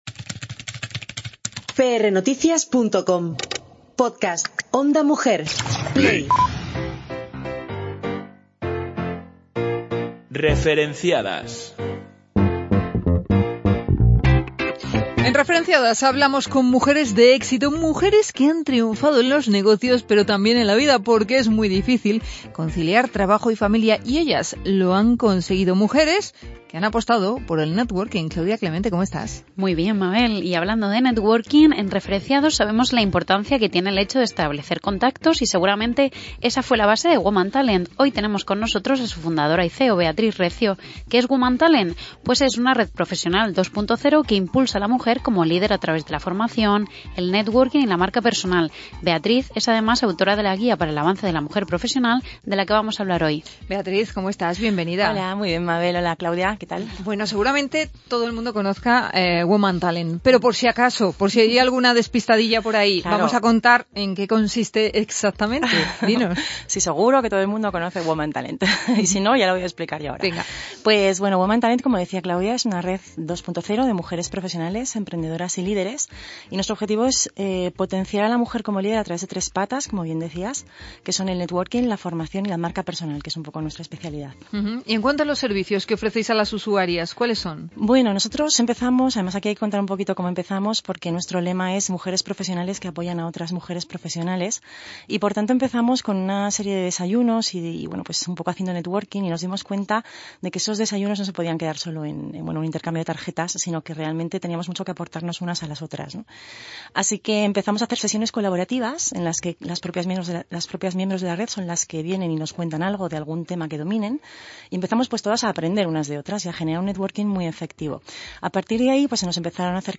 Toda la entrevista, ampliada, la encontrarás en nuestro Podcast.